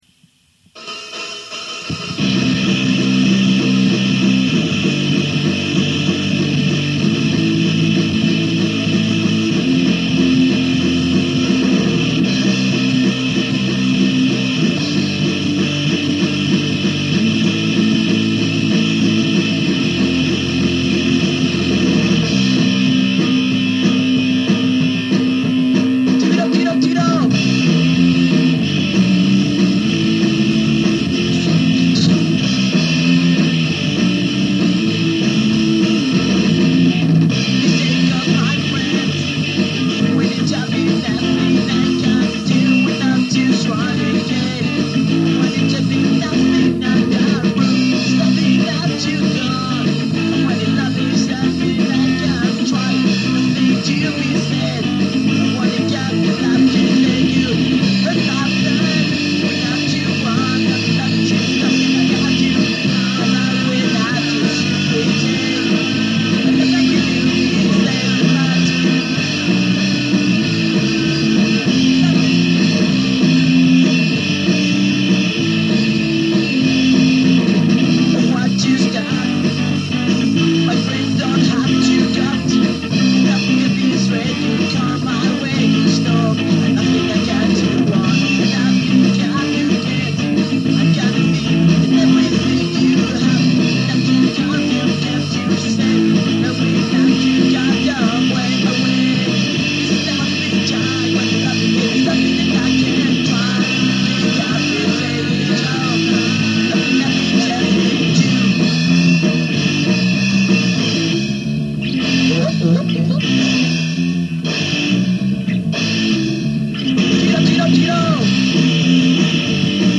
La calidad no es de las mejores